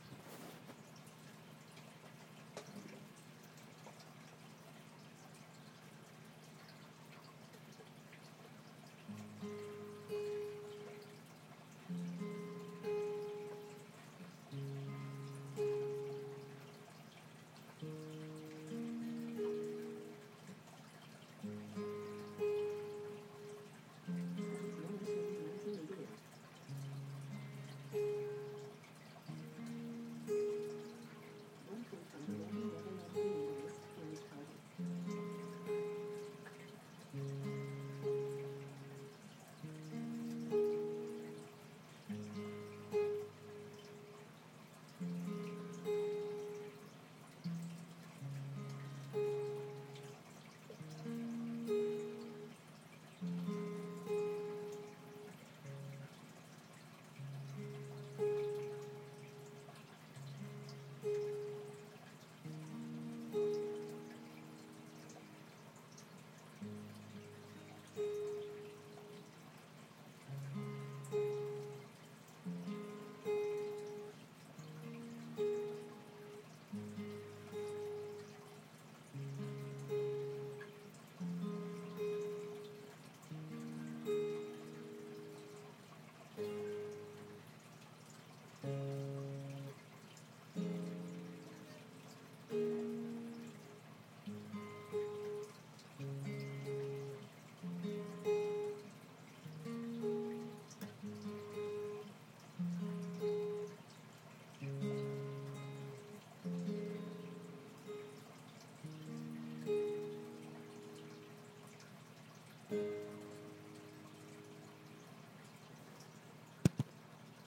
more random guitar playing